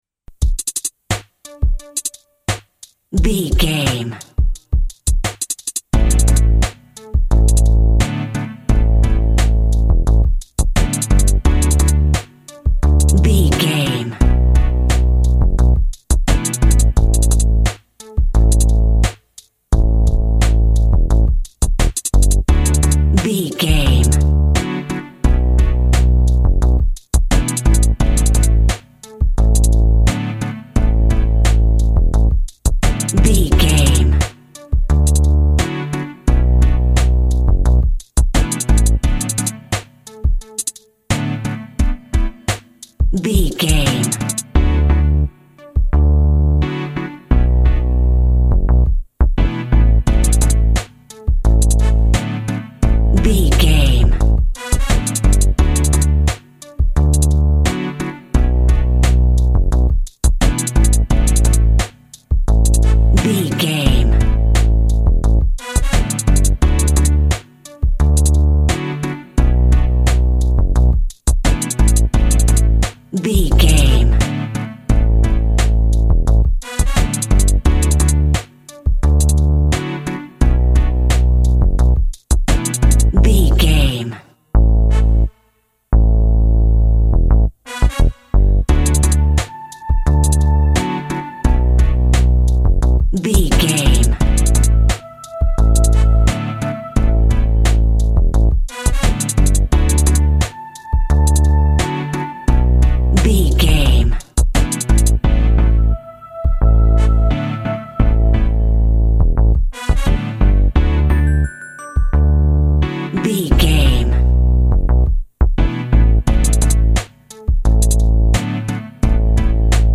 Ionian/Major
D
hip hop
R+B
synth lead
synth bass
hip hop synths